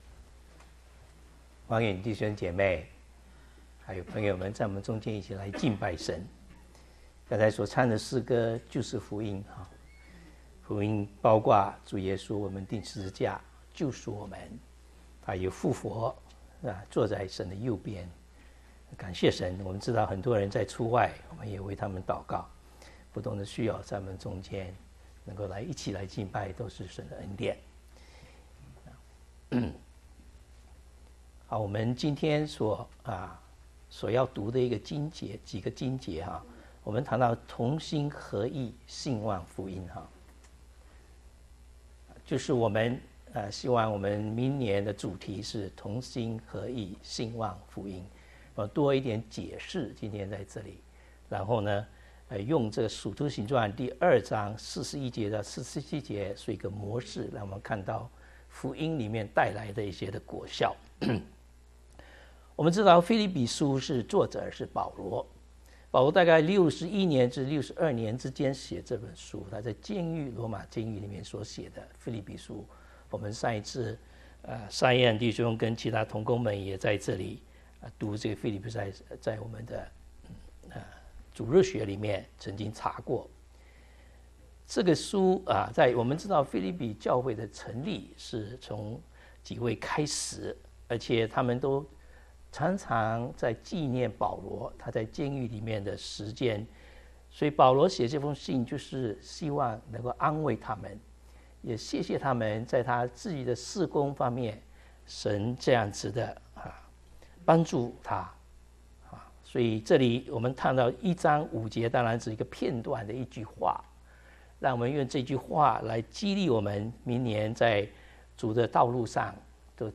講員